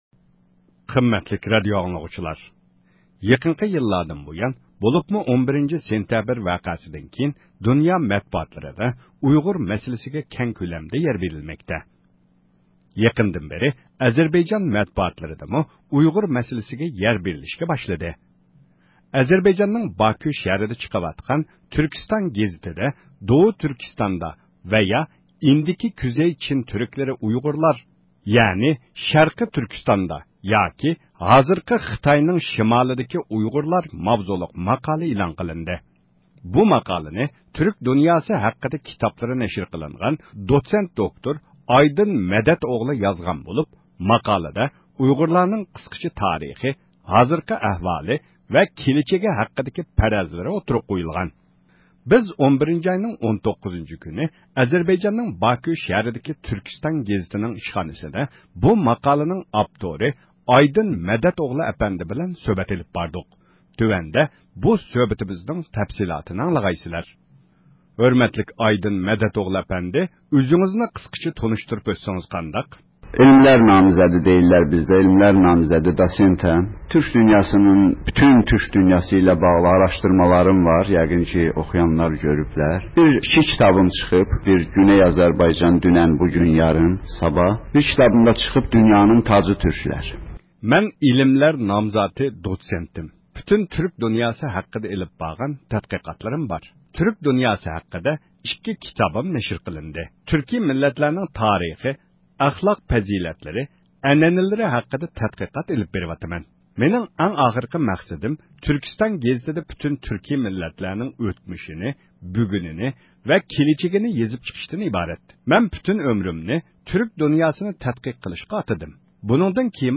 سۆھبەت ئېلىپ باردۇق